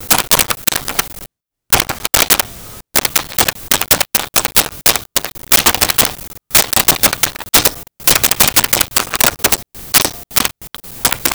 Computer Type Slow
Computer Type Slow.wav